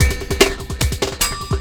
Percussion 20.wav